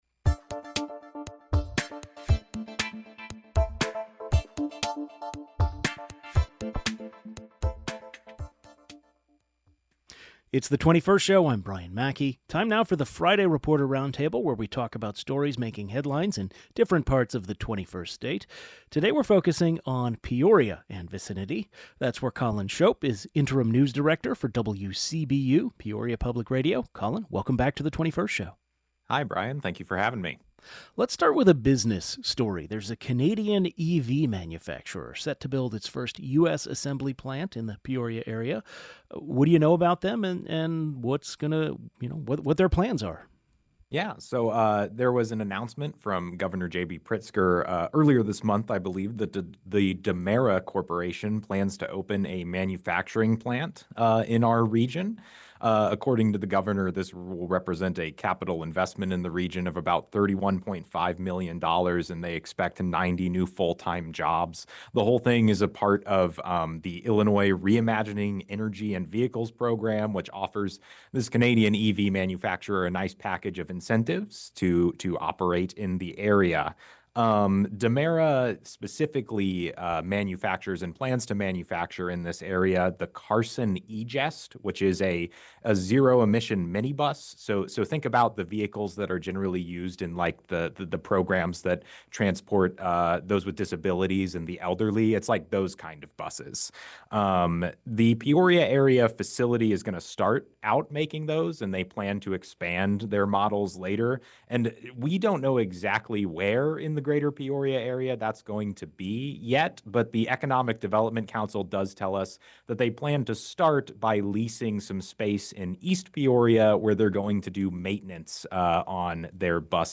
Guest: